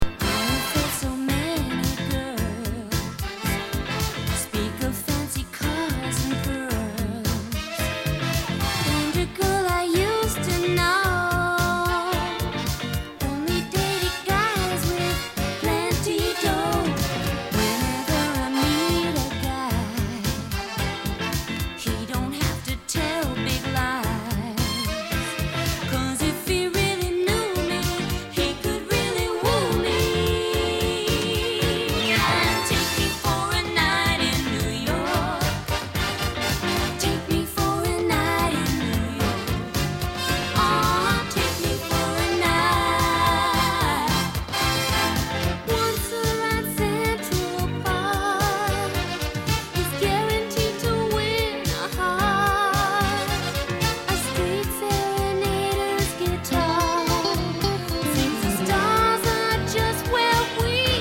un petit bijou jazzy